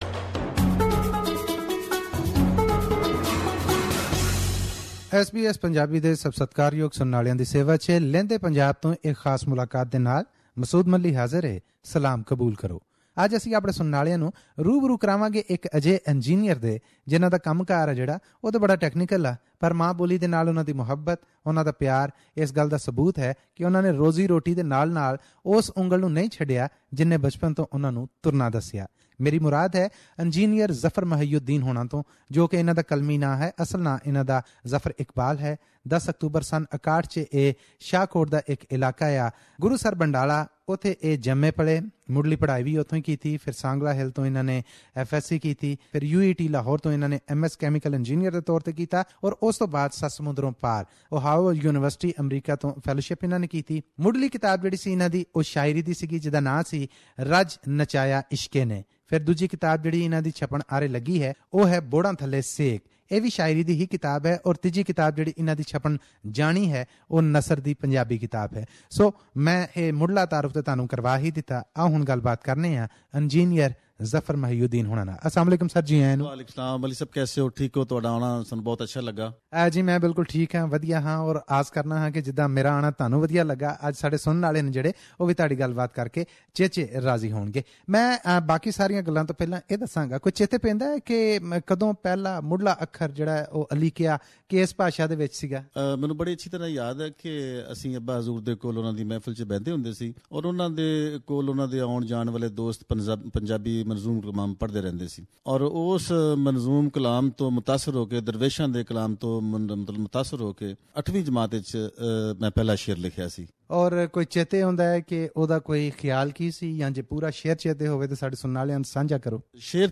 He has written many books of Punjabi Poems and shares some of them with us here in this interview.